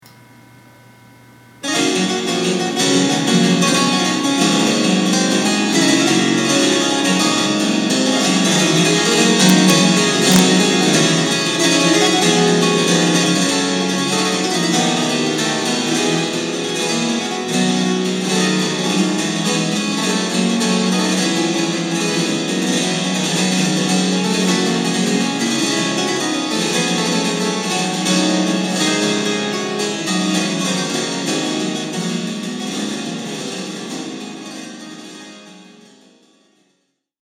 epinette des vosges
oui l'epinette ca fait penser au clavecin et c'est plutot ca..
epinette
epinett.mp3